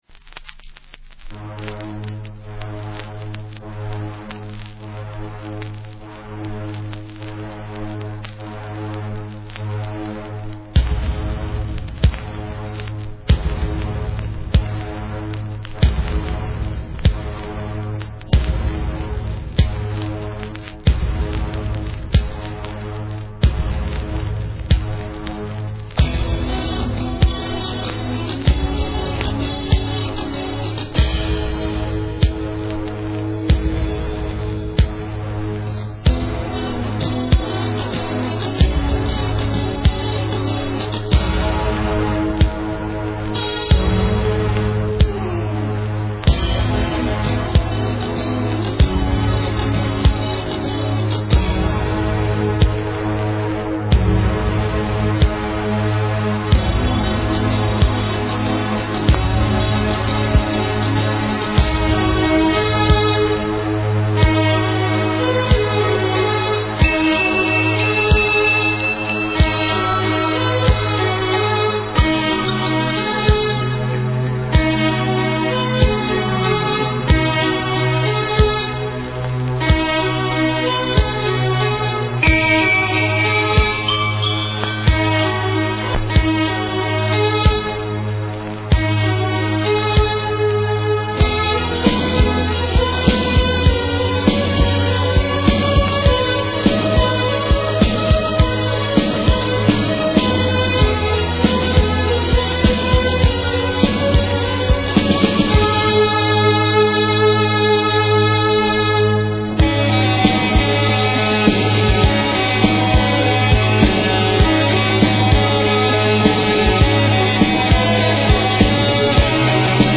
Cold wave Unique 45t retour à l'accueil